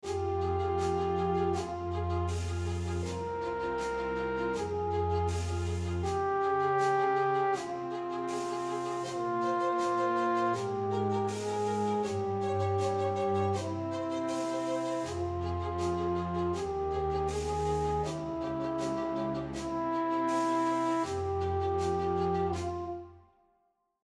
Loop Underscore